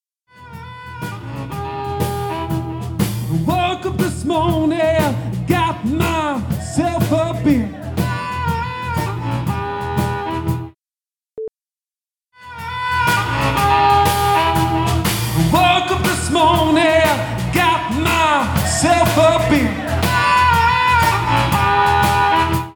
Mixing a Live Band from a Zoom H4n Stereo Recording?
I recorded a live show of my band (vocals, harmonica, guitar, bass, and drums) using a Zoom H4n in stereo (internal mics).
Compression can help to tame the drums (which have peaks that slightly dominate the mix) and allow previously buried sounds to be brought more to the fore.
Just to give an example of the kind of variety that can be achieved, here’s a before/after where the after uses pretty heavy compression (using the built in compressor from Audacity 1.3.12), the treble is raised a bit (centred around 2.5 kHz) and the mid-bass (around 200-250 Hz) is dropped down.